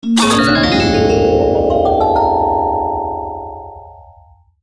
UI_SFX_Pack_61_5.wav